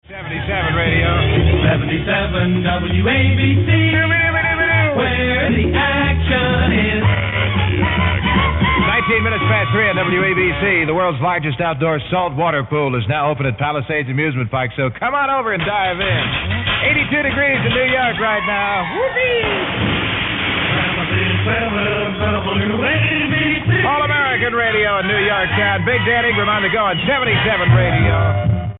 WABC commercial read by Dan Ingram - 1964